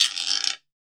HLGUIRO.wav